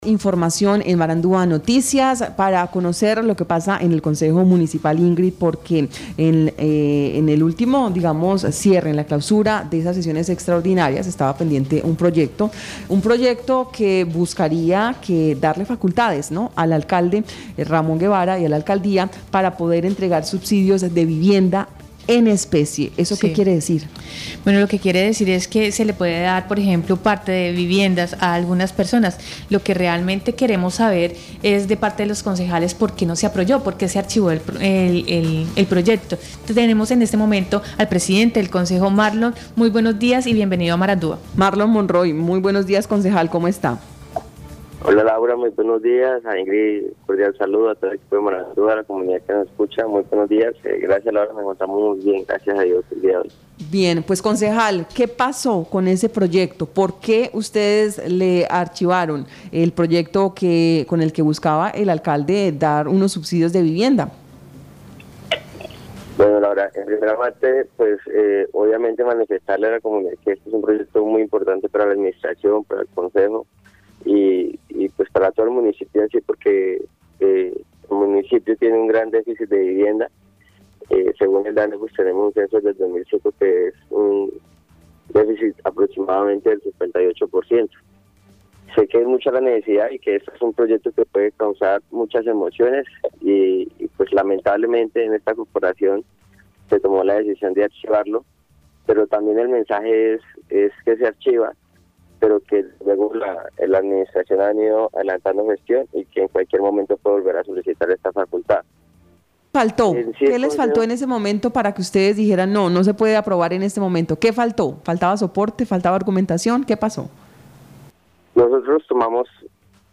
Escuche a Marlon Monroy, presidente Concejo de San José del Guaviare.